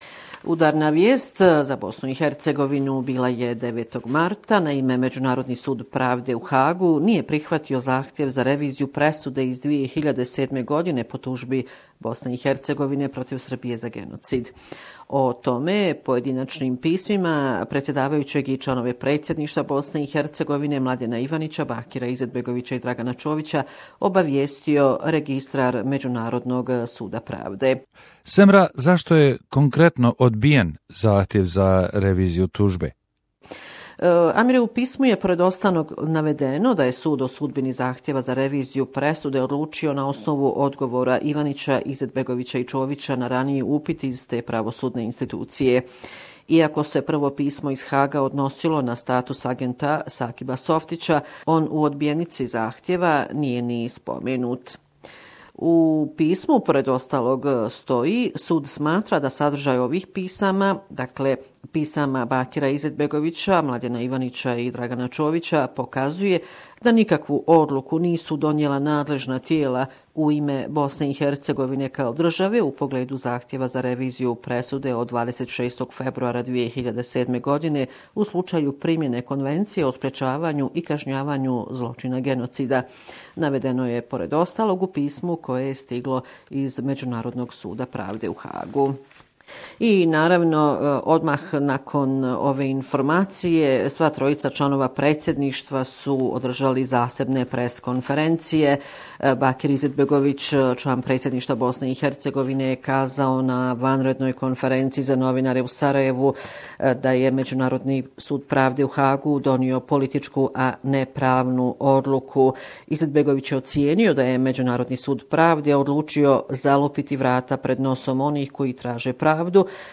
UN Court decision to decline revision of Bosnias genocide case against Serbia caused internal political blame and step down calls Last week report from Bosnia and Herzegovina